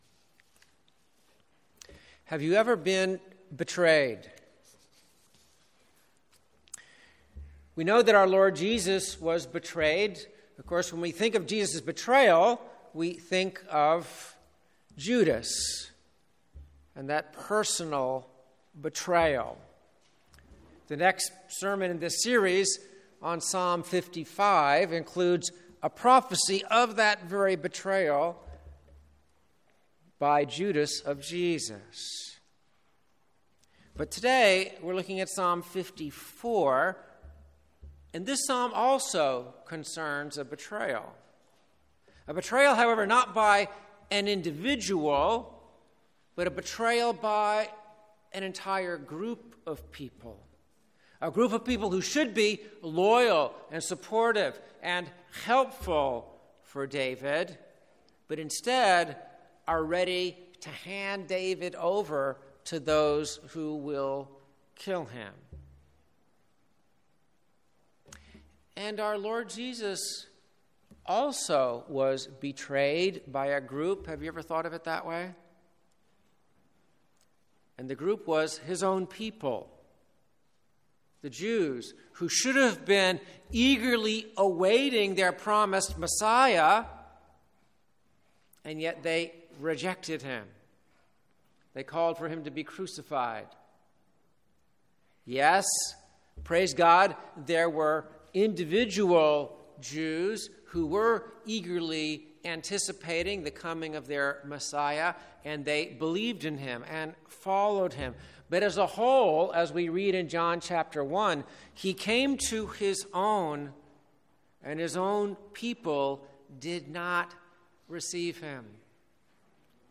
2019 Sermon